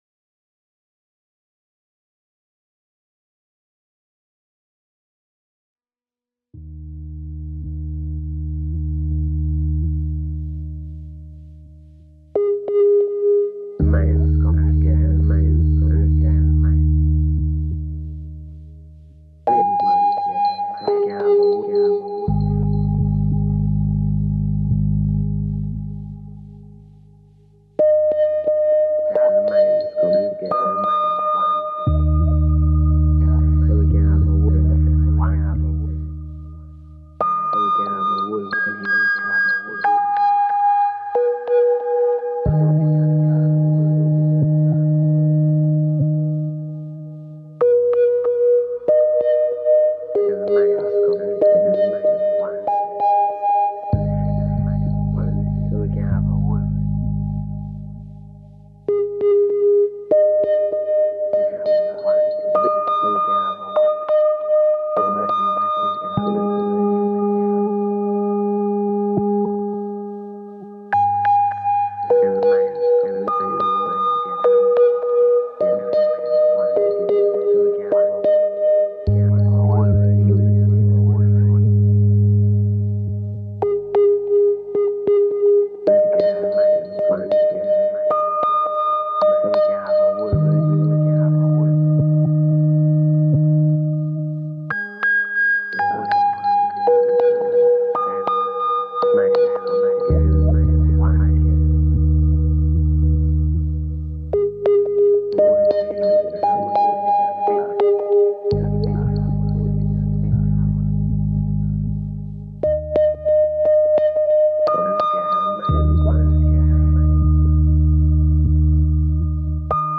A Modular Synth piece
minimalist tintinnabuli technique